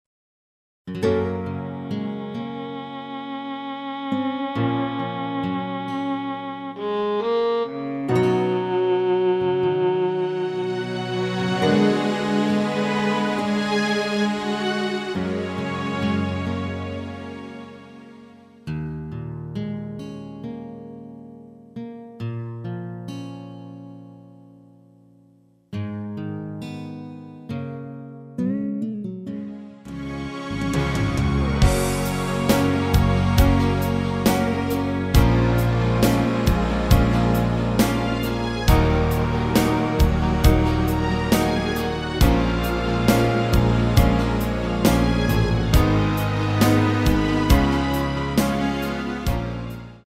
F#
앞부분30초, 뒷부분30초씩 편집해서 올려 드리고 있습니다.